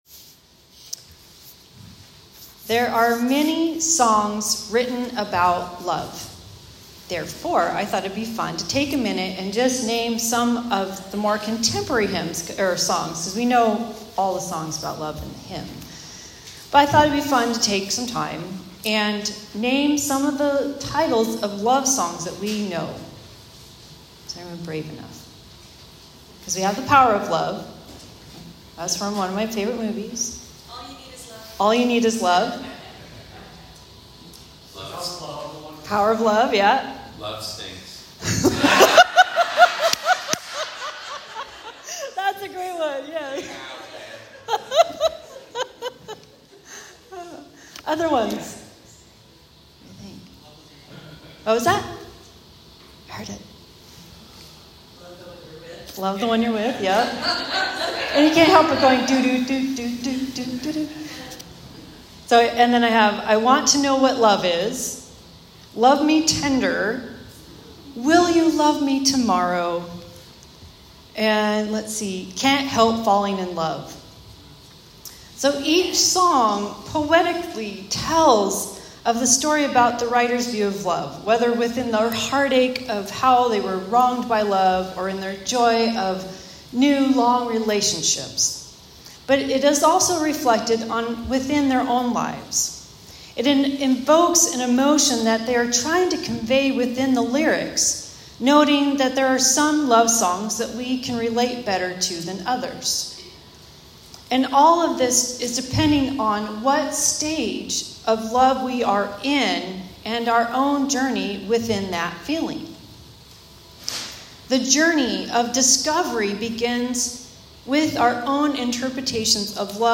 Sermons | Fir-Conway Lutheran Church